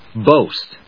/bóʊst(米国英語), bˈəʊst(英国英語)/